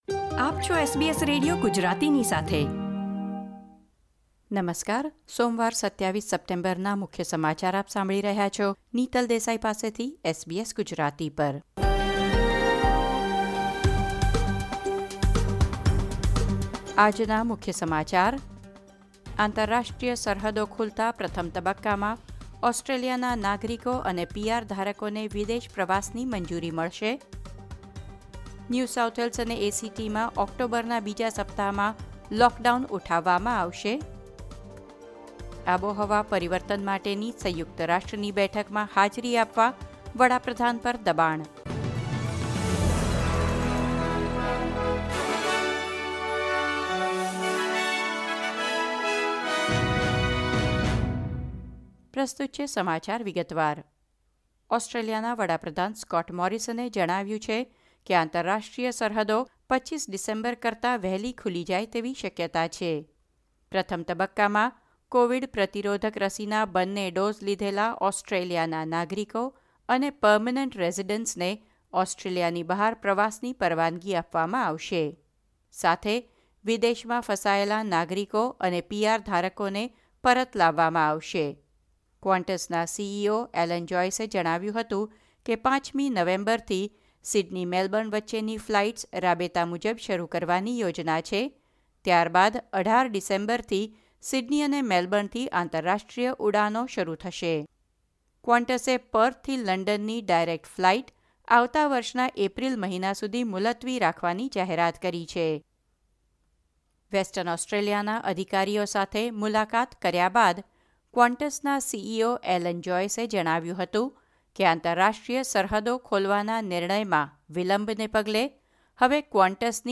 SBS Gujarati News Bulletin 27 September 2021